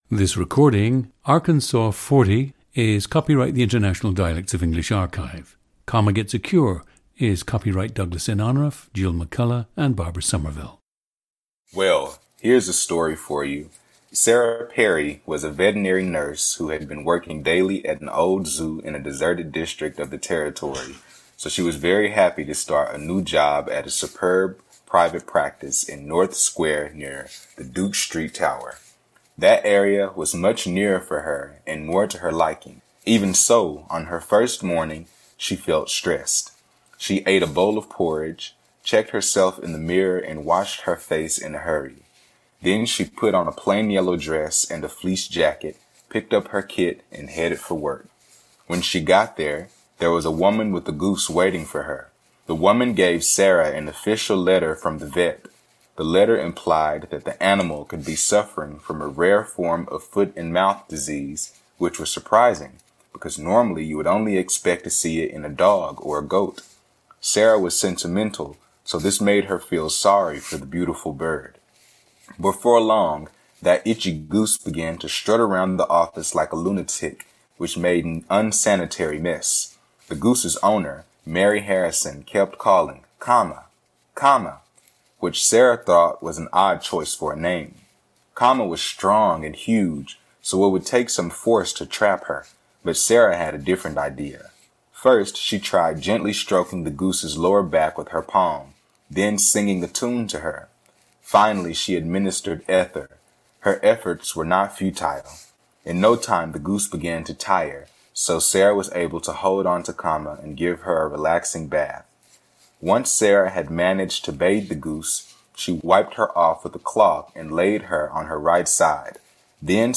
GENDER: male
The subject says his family spoke typical African-American slang, in addition to country slang.
GOOSE [u] words are often formed with a low tongue position (duke, tune).
DRESS [e] words occasionally use short vowel [ɪ] KIT (then, get).
Ending consonant [t] is either unformed or forms as a glottal stop when it ends a word (start, that, first).
3. The diphthong can be monophthongized (implied, time, fire, tried, liking).
The recordings average four minutes in length and feature both the reading of one of two standard passages, and some unscripted speech.